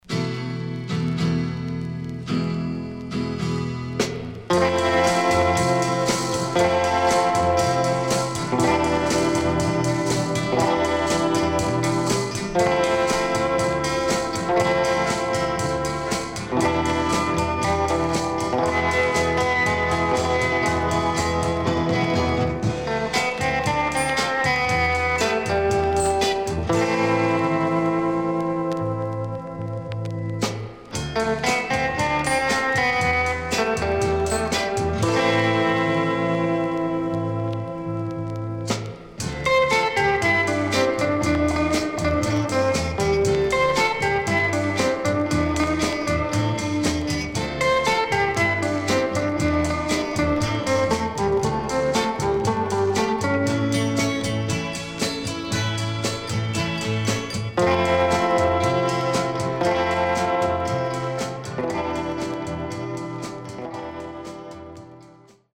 HOME > Back Order [SOUL / OTHERS]
SIDE A:所々チリノイズがあり、少しプチノイズ入ります。